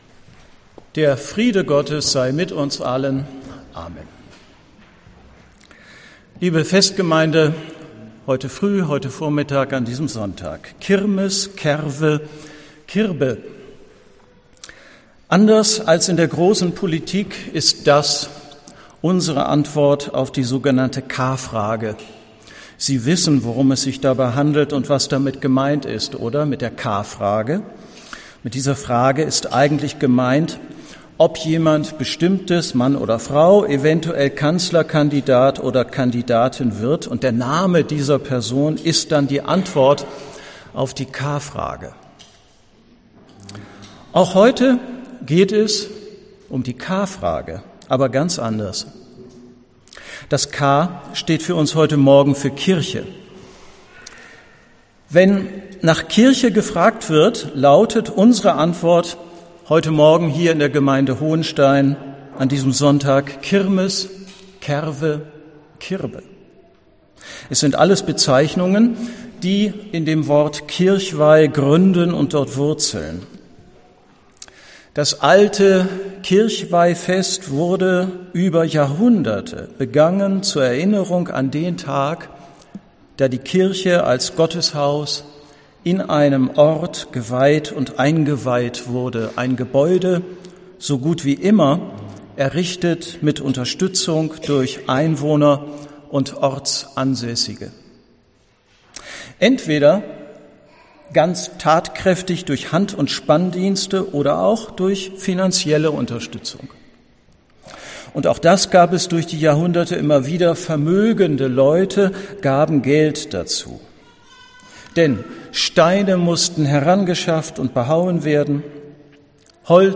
Die K-Frage – Predigt zur Kirbe